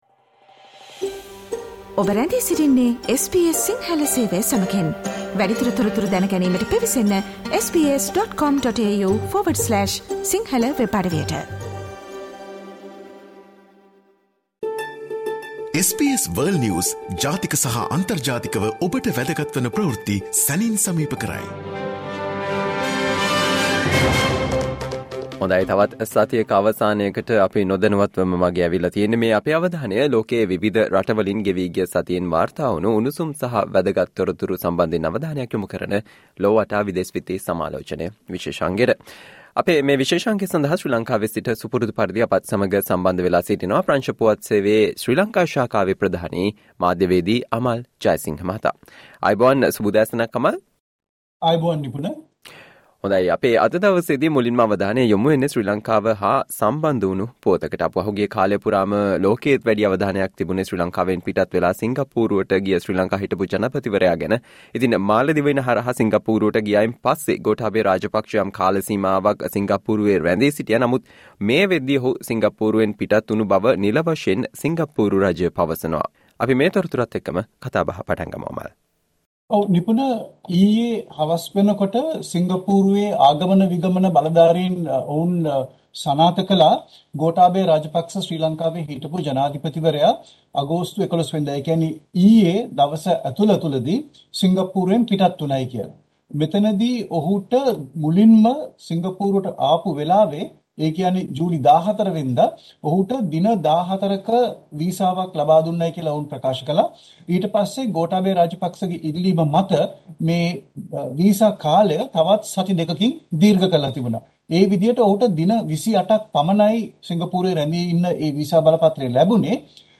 World's prominent news highlights in a few minutes - listen to the SBS Sinhala Radio weekly world News wrap every Friday.